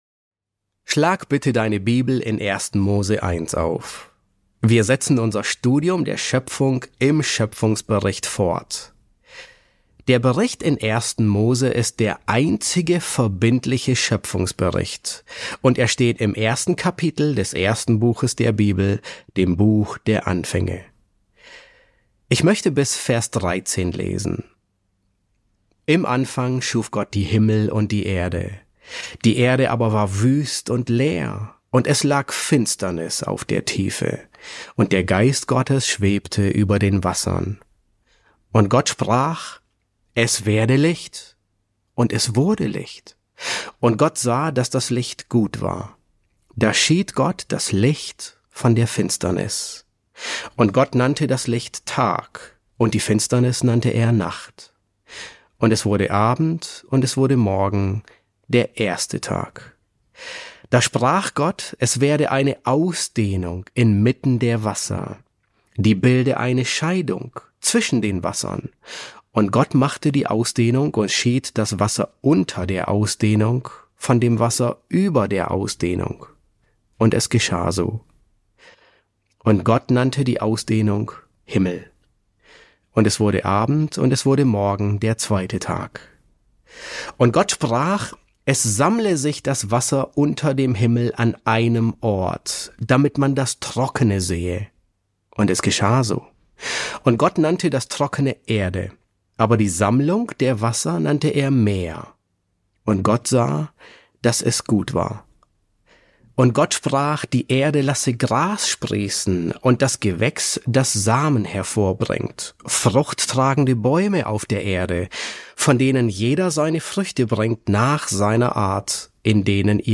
E7 S6 | Der 3. Schöpfungstag ~ John MacArthur Predigten auf Deutsch Podcast